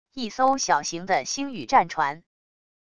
一艘小型的星宇战船wav音频